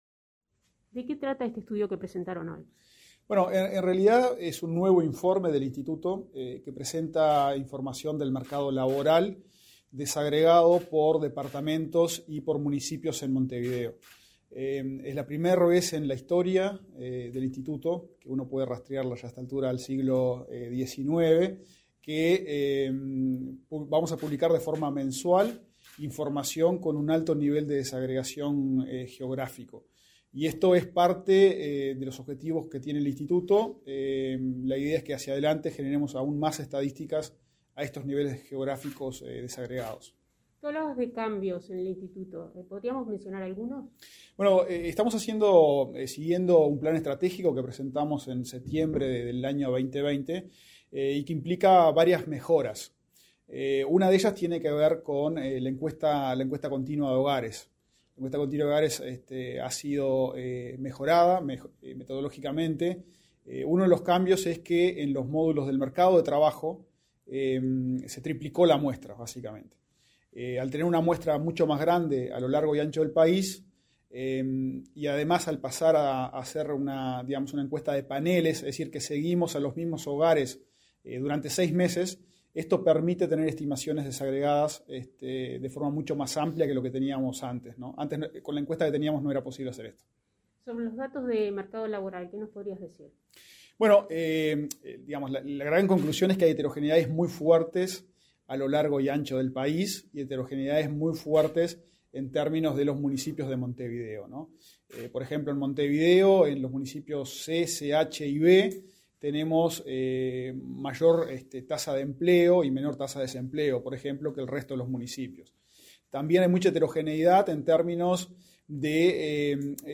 Entrevista al director del INE, Diego Aboal